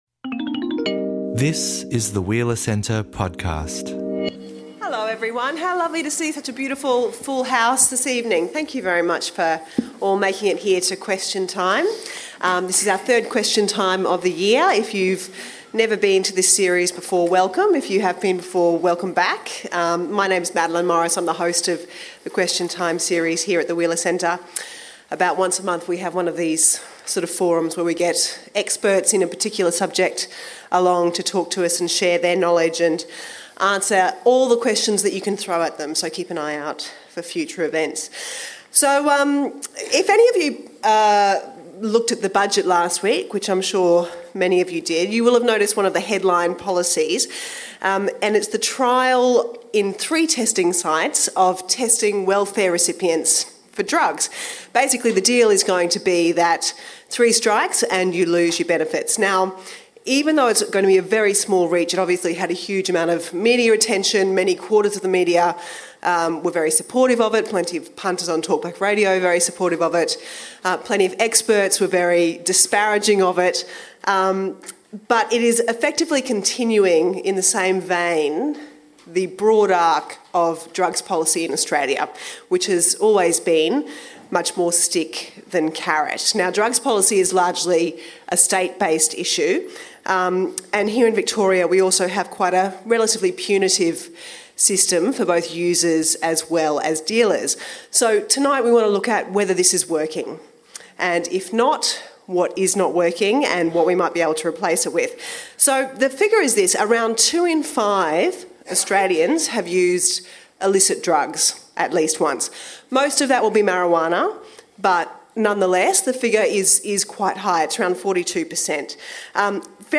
Our panel tackle questions from the audience in a full hour of Q&A.